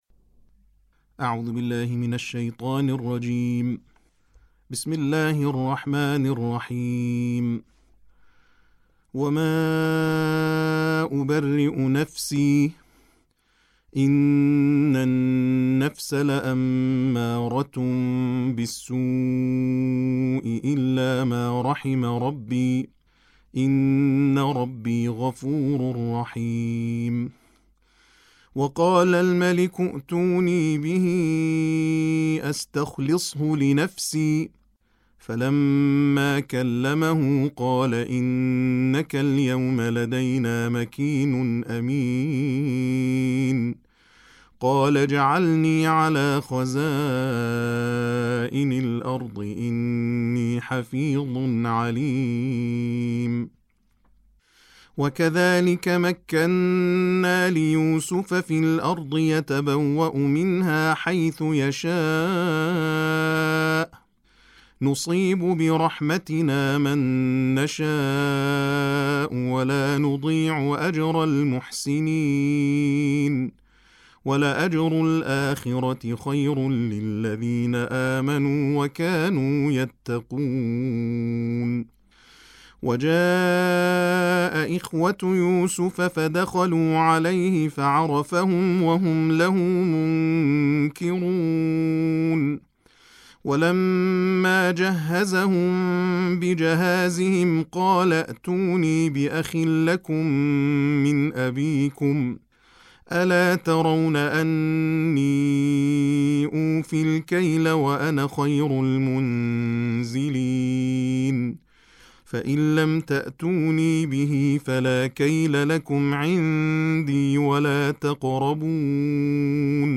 تلاوت ترتیل جزء سیزدهم قرآن